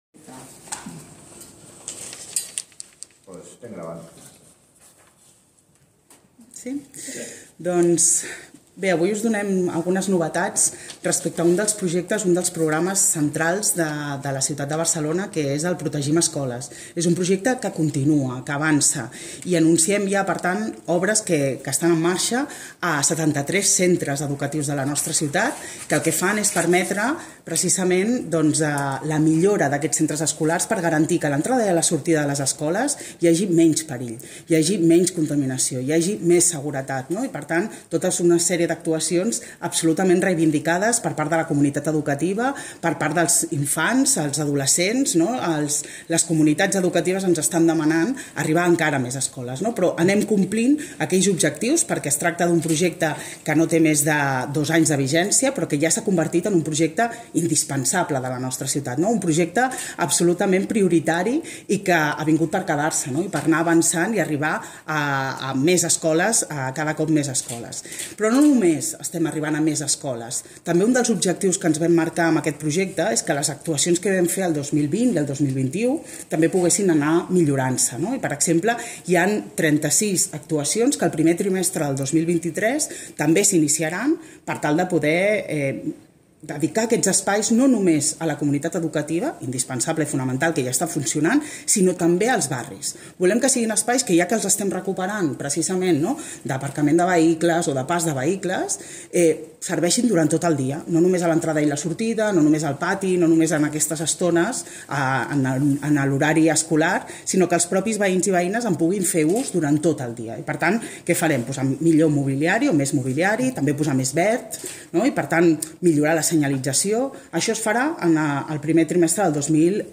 Declaracions de Janet Sanz i Gemma Tarafa